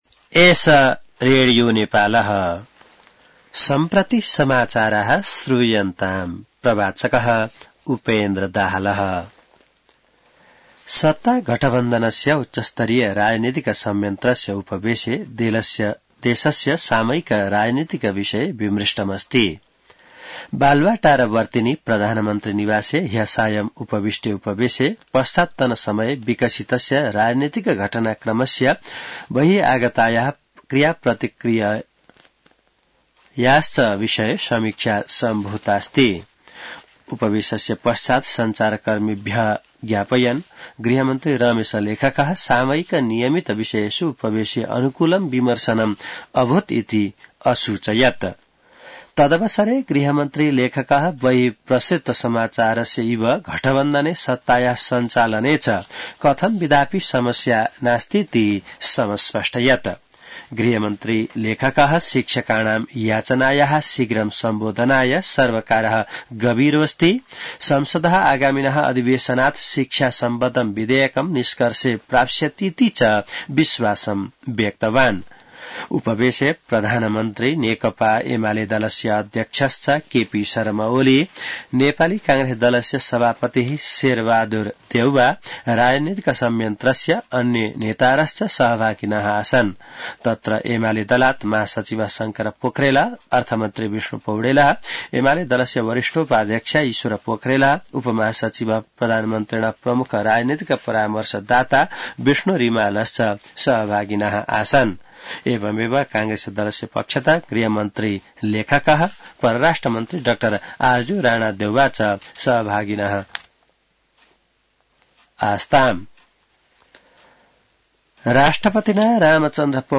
An online outlet of Nepal's national radio broadcaster
संस्कृत समाचार : ४ वैशाख , २०८२